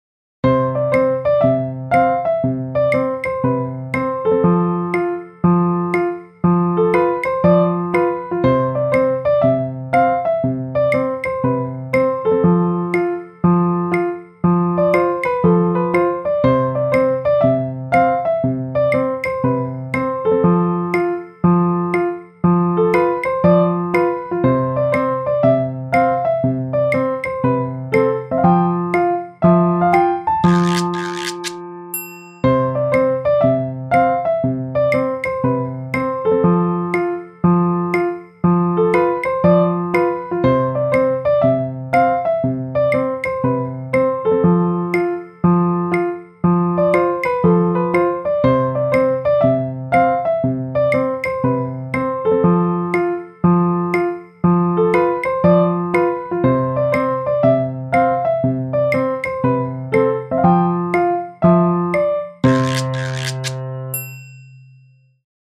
ぽかぽか陽気の日、ほのぼの、リラックス、ふんわりのイメージで作曲しました。